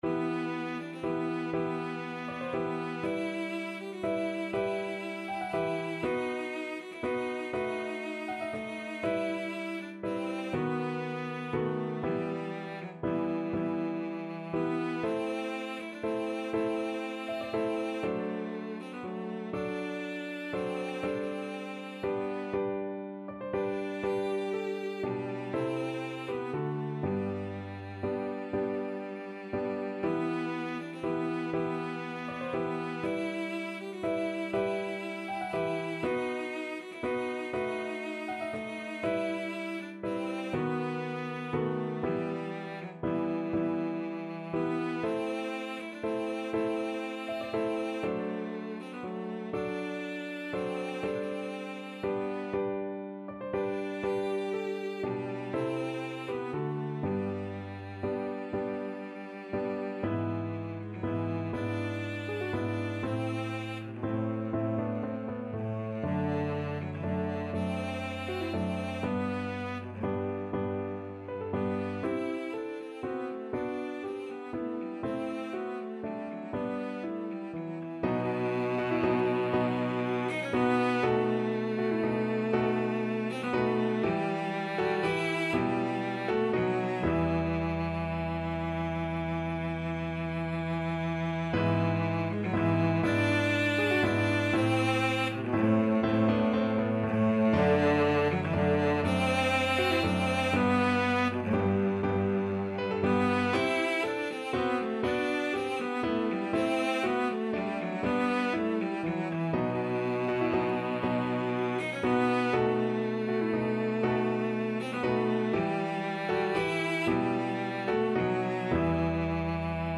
Cello
12/8 (View more 12/8 Music)
G major (Sounding Pitch) (View more G major Music for Cello )
G3-G5
~ = 100 Largo .=40
Classical (View more Classical Cello Music)